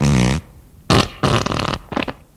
文件夹里的屁 " 屁04
描述：从freesound上下载CC0，切片，重采样到44khZ，16位，单声道，文件中没有大块信息。准备使用！在1个文件夹中有47个屁;）
Tag: 喜剧 放屁 效果 SFX soundfx 声音